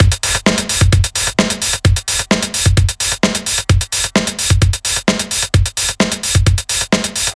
• 130 bpm punk drumloop.wav
A fast paced and pretty hard hitting punk style drumloop (Kick tuned to F#)
130_bpm_punk_drumloop_sUJ.wav